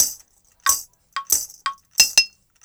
89-PERC2.wav